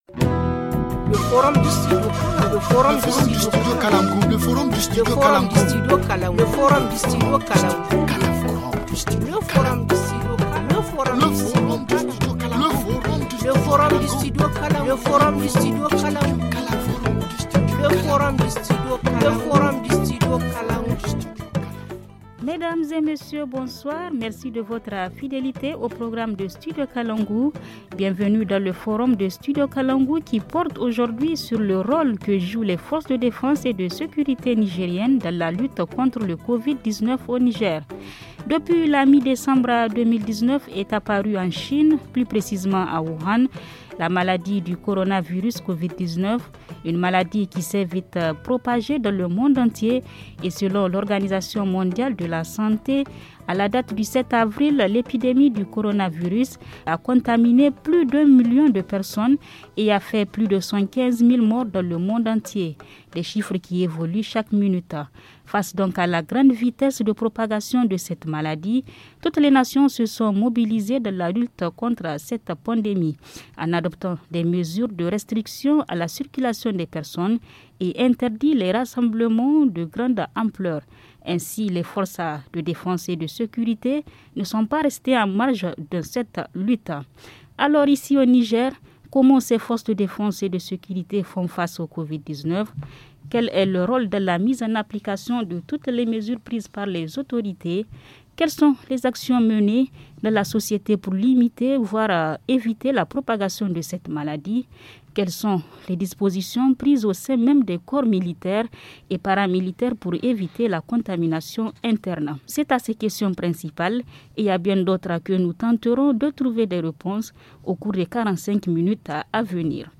Le média Studio Kalangou, qui diffuse ses programmes à travers une quarantaine de radios partenaires sur tout le territoire nigérien, a produit et diffusé le mardi 7 avril un forum consacré au rôle des FDS dans la lutte contre le Covid-19.